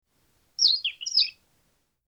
Birds Chirping #1 | TLIU Studios
Category: Animal Mood: Calming Editor's Choice